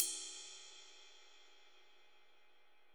RIDE 4.wav